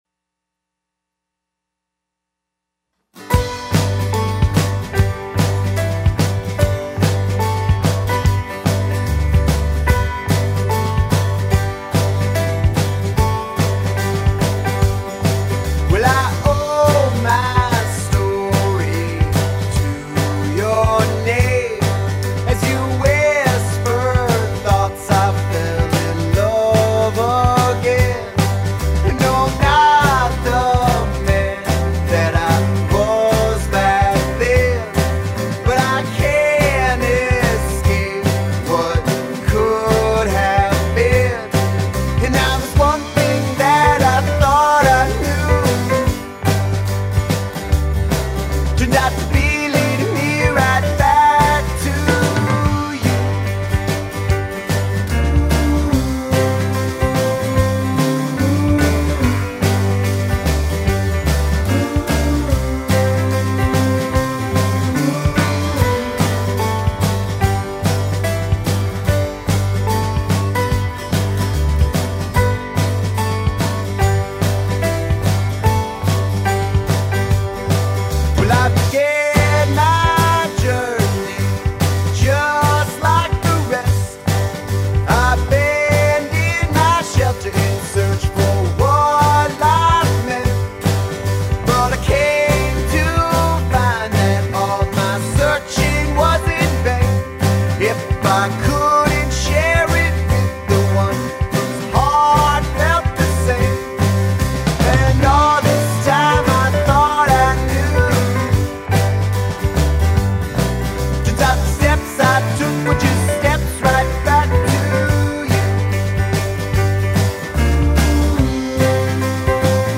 They’re male led and they sound great.